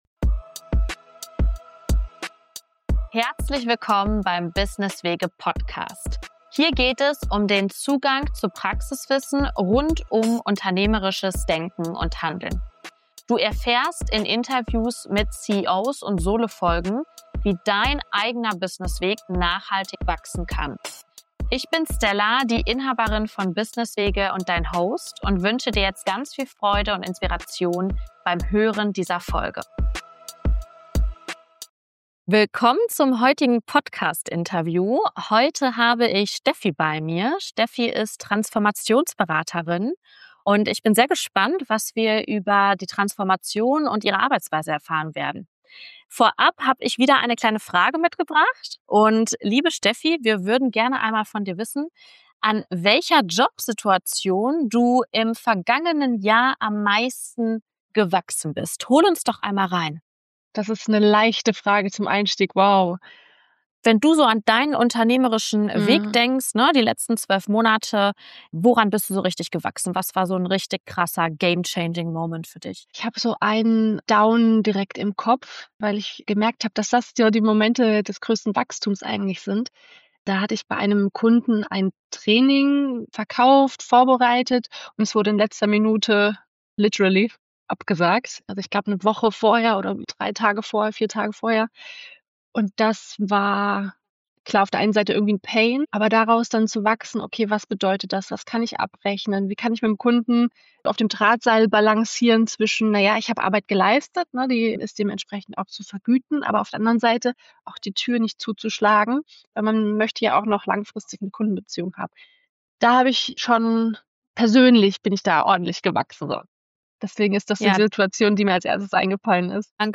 CEO Talks